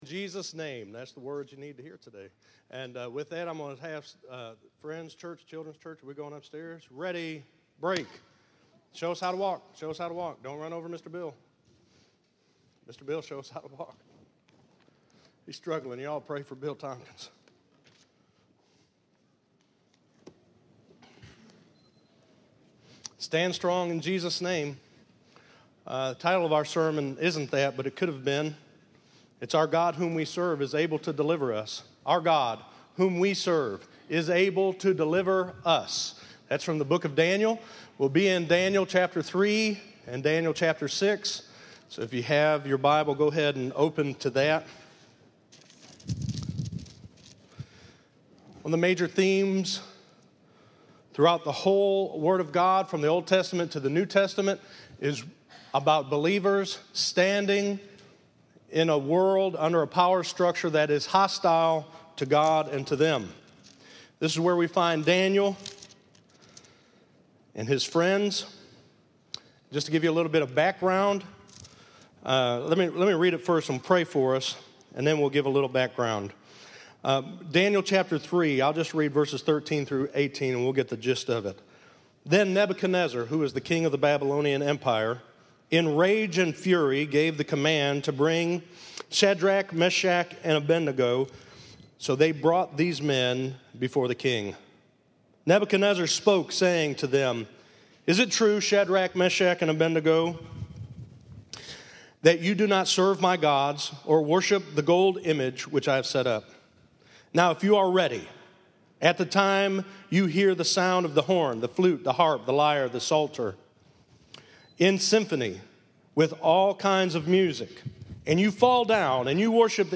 Listen to Our God Whom We Serve Is Able To Deliver Us - 09_06_15_sermon.mp3